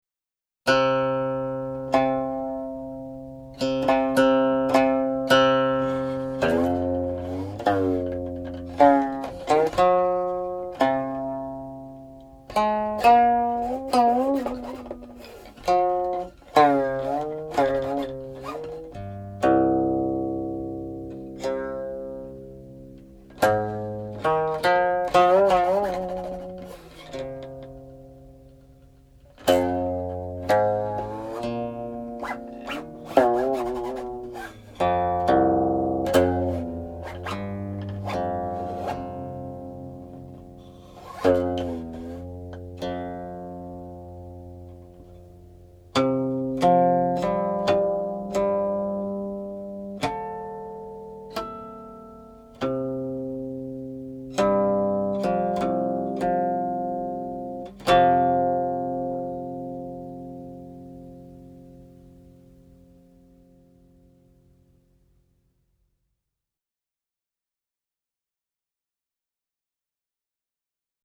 One section13
(00.45) -- harmonics
(01.04) -- Modal prelude ends